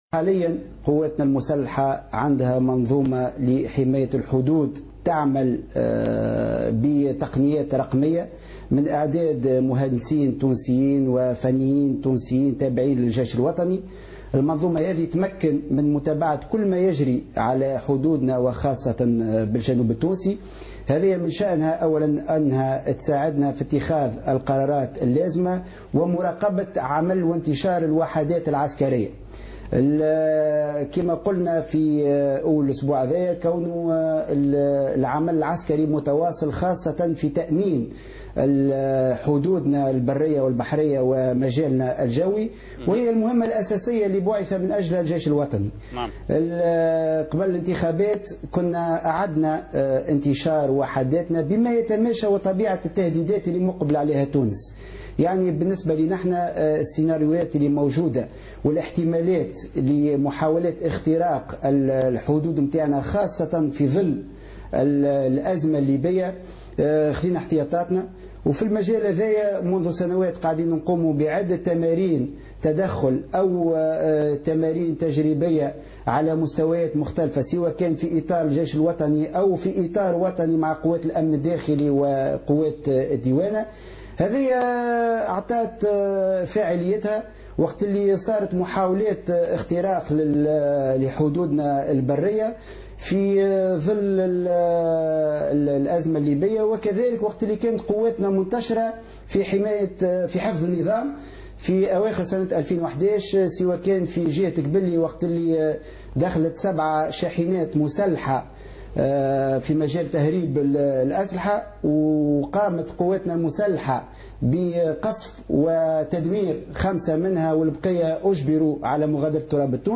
تصريح للقناة الوطنية الأولى